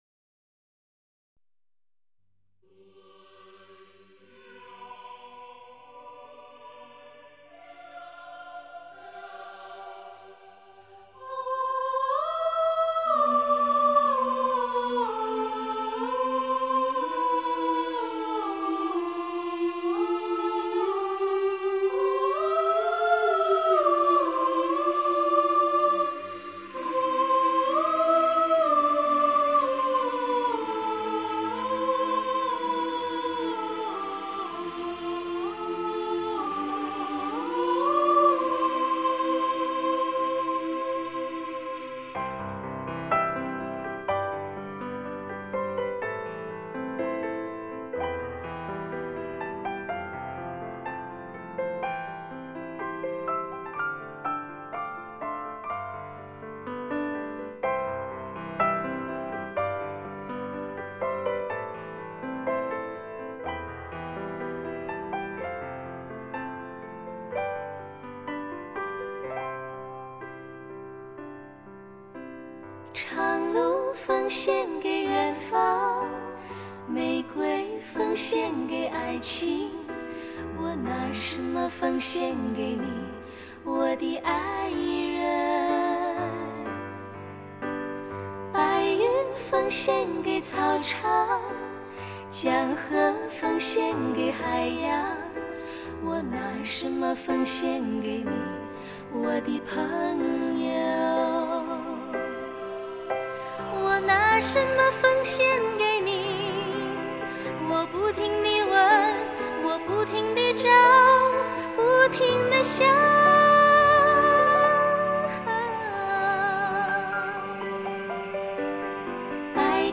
这是一张值得细意品尝的人声佳作。
态度严谨、充满节奏感及生命力的歌声，同时洋溢着亲切、纯真、甜美与暖洋洋的气氛，令人听得相当惬意。
中国歌曲